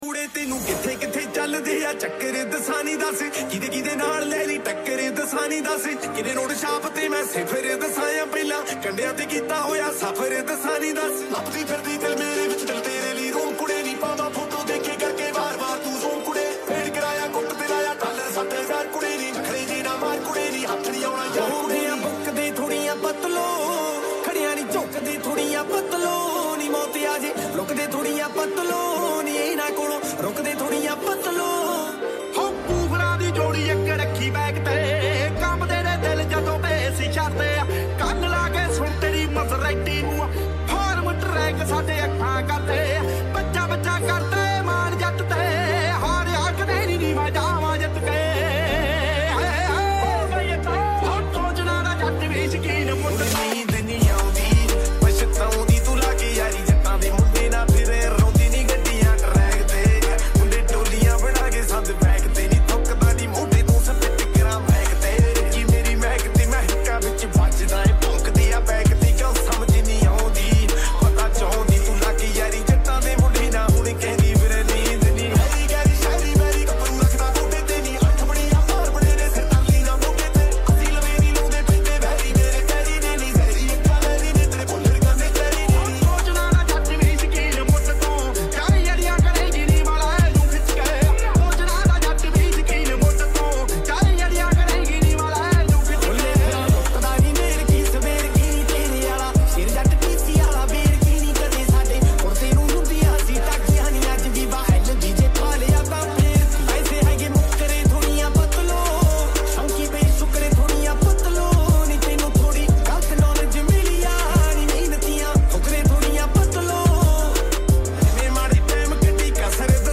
MASHUP SONG